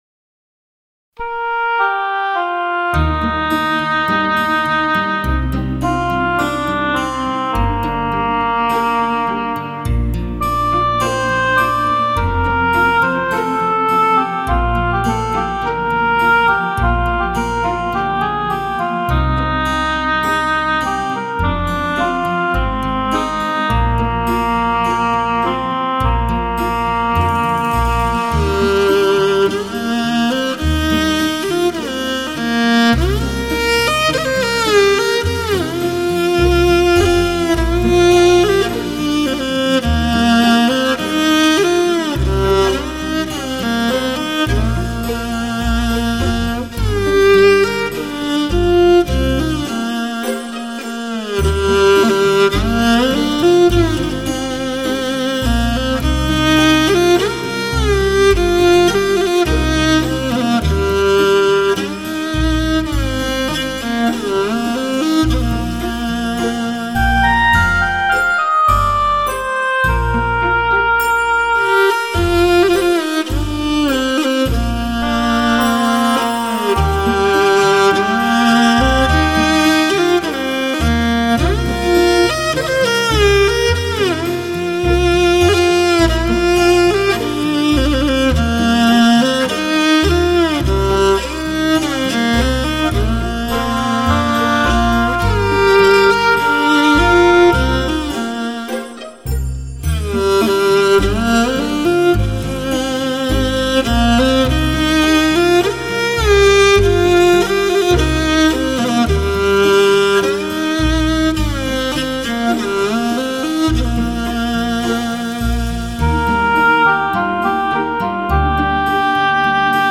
音色柔美纯正，旋律醇美悠扬。